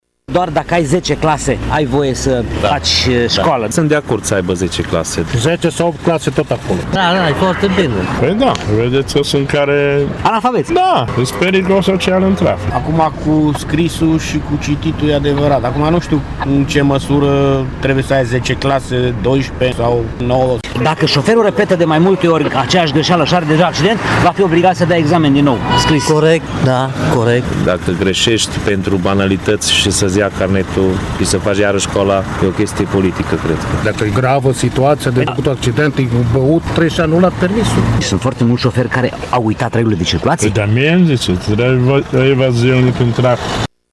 Participanții la trafic sunt, în general, de acord cu aceste condiții.